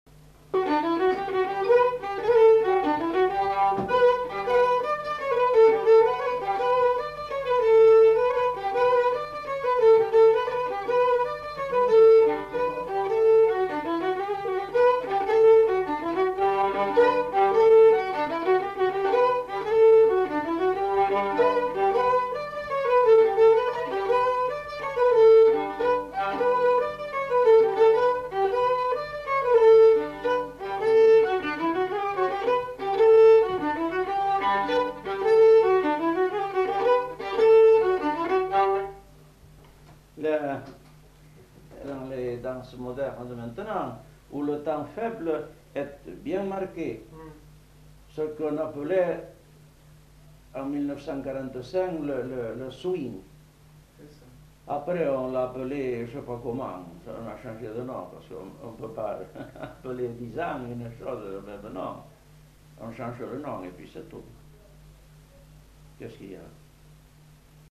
Aire culturelle : Gabardan
Genre : morceau instrumental
Instrument de musique : violon
Danse : rondeau